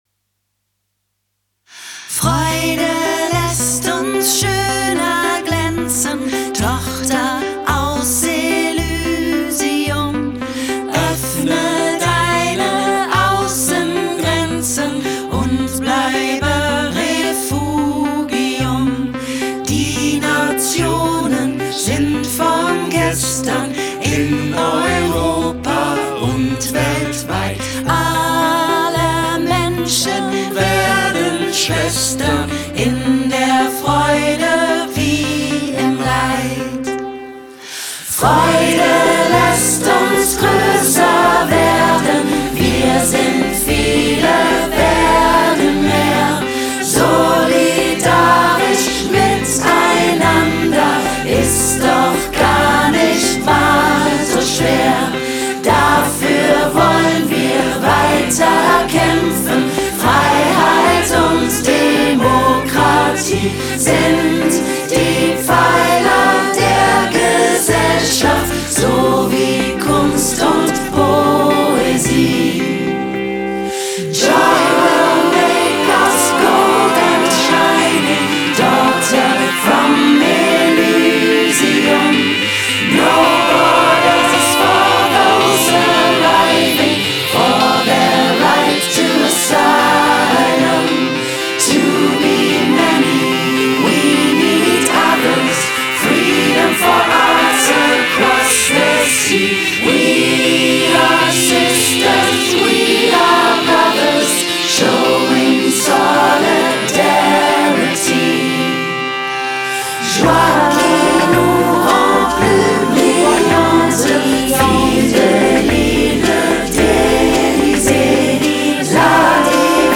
AUDIO (DEMO MIX)